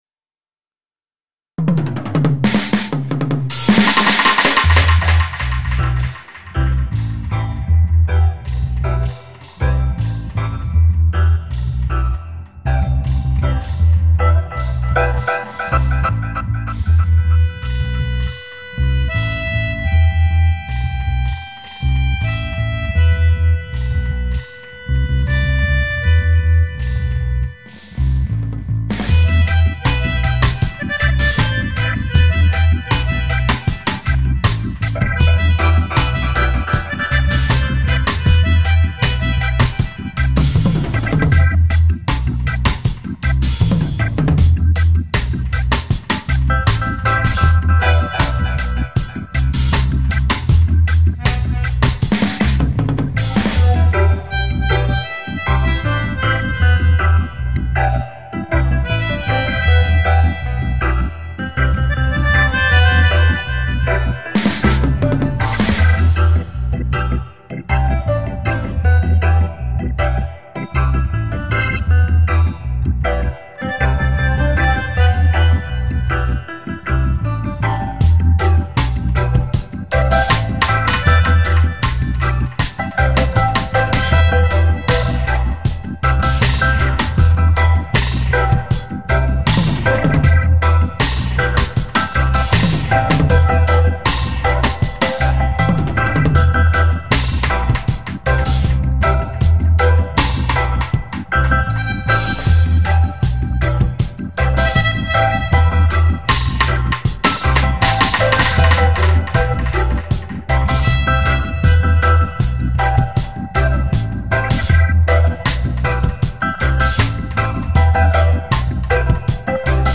spring-2011-dub-K5tjD